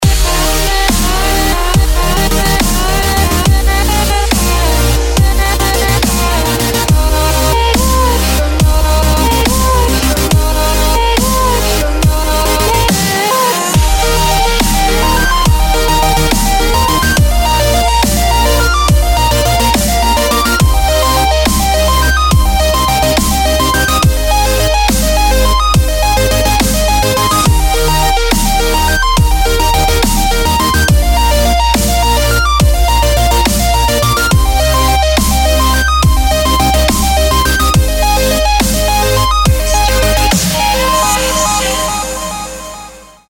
• Качество: 192, Stereo
Отличная Дабстеп Мелодия для телефона!